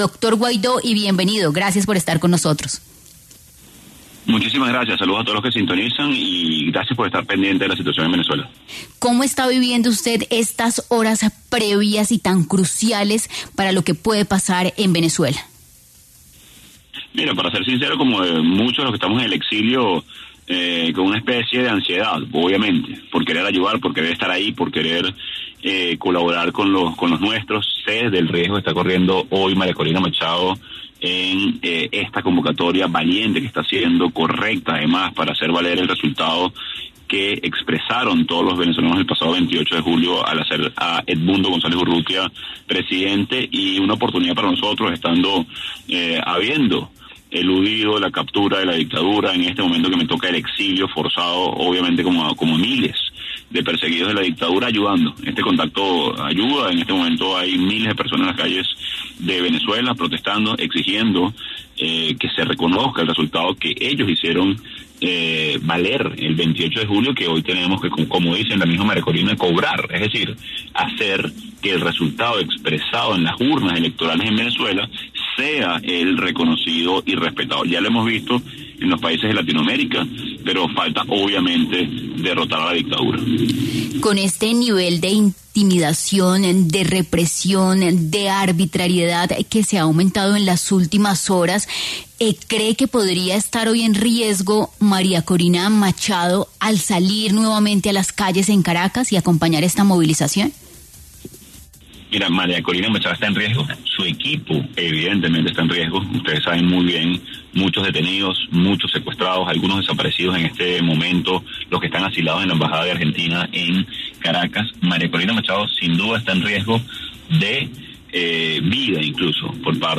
En Caracol Radio estuvo Juan Guaidó, líder opositor y expresidente interino de Venezuela, quien habló sobre la realidad que vive Venezuela a pocas horas de que inicie el nuevo periodo presidencial